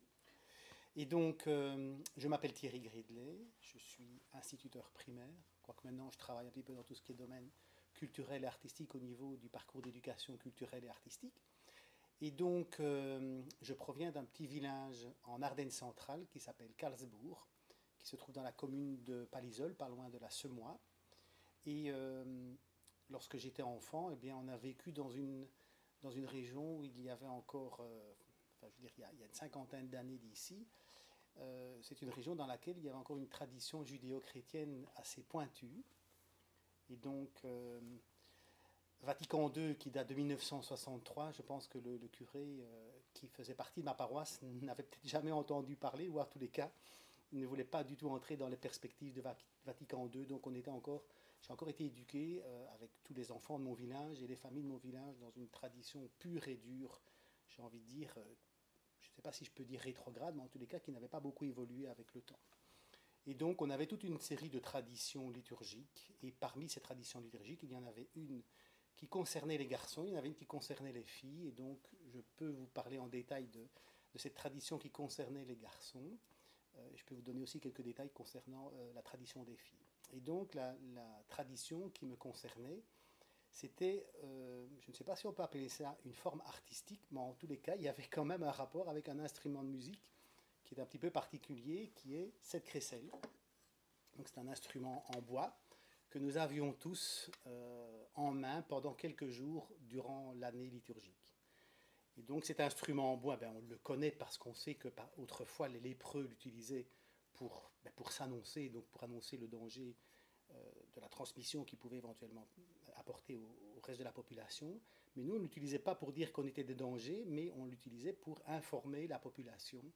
Genre : parole Type : témoignage
Lieu d'enregistrement : Institut Supérieur Royal de Musique et de Pédagogie (Namur)